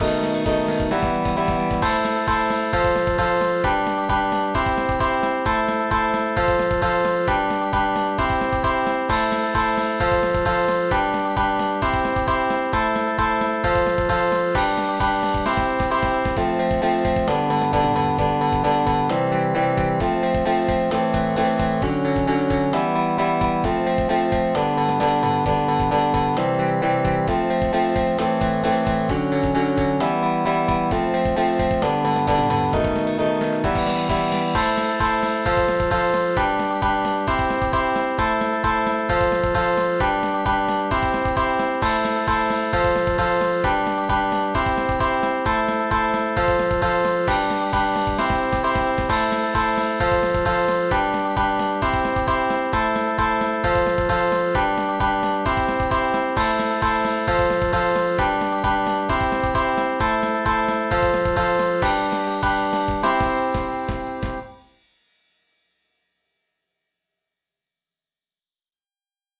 MPEG ADTS, layer III, v2, 128 kbps, 16 kHz, Monaural